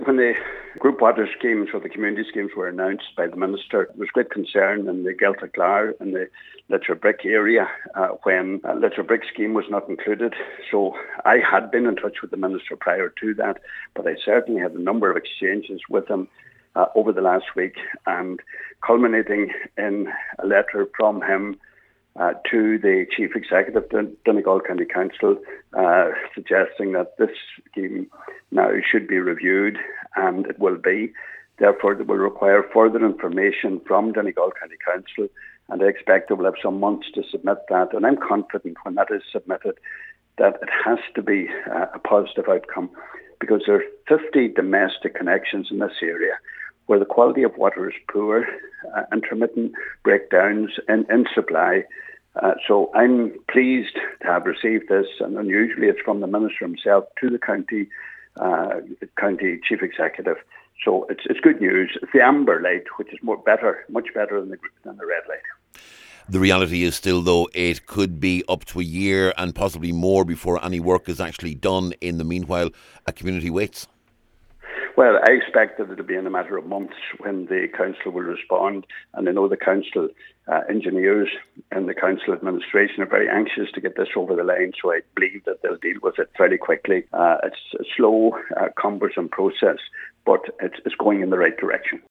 Election Candidate Pat the Cope says that needed to be addressed, and hopes that will happen as quickly as possible……………